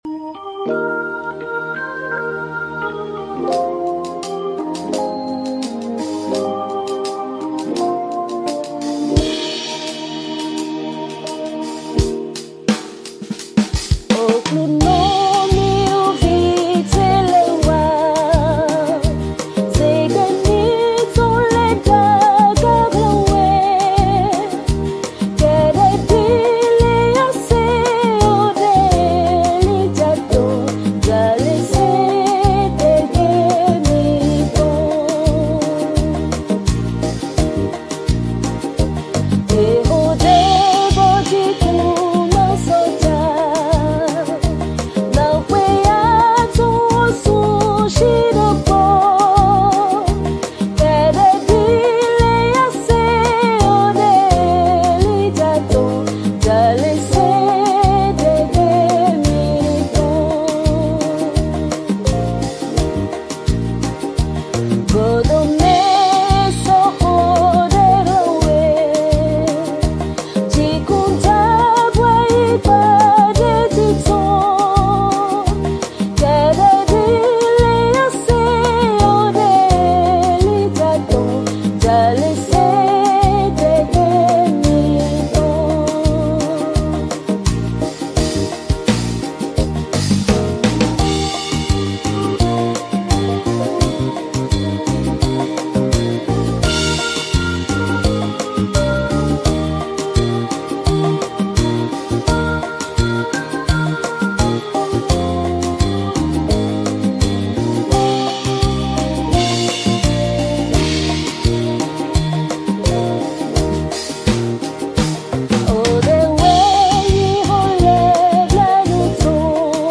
Chants RWANDAIS
Clarinette / Guitare / Synthé /